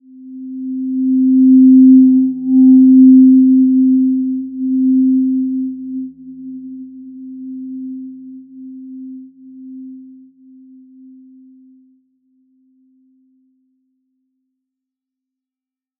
Simple-Glow-C4-mf.wav